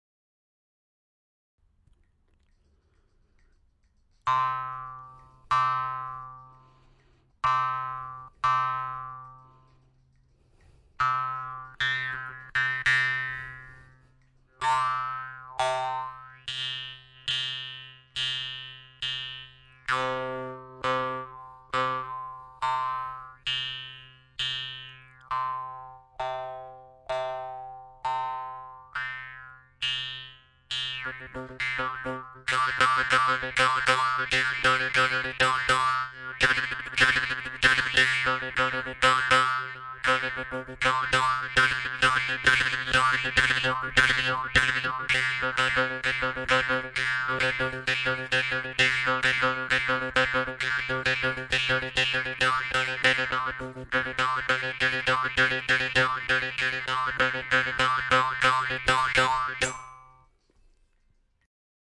D调中的吉姆巴德